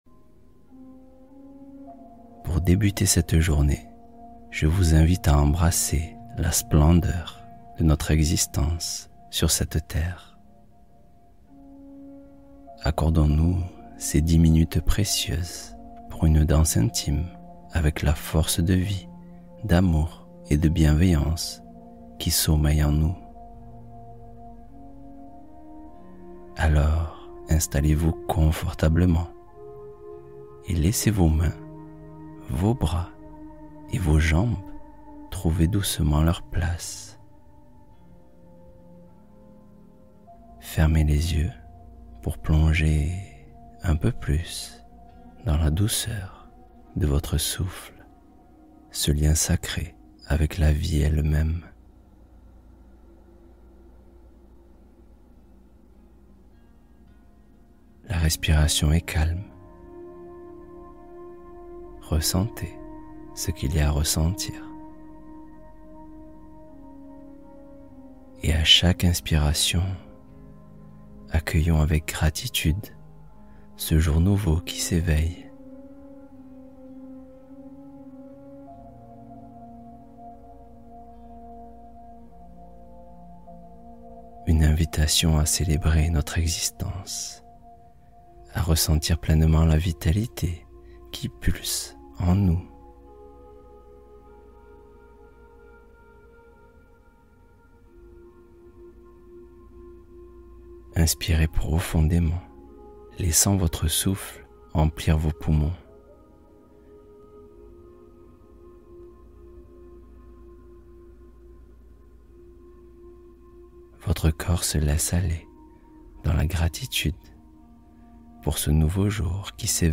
Exploration intérieure apaisante — Voyage guidé de détente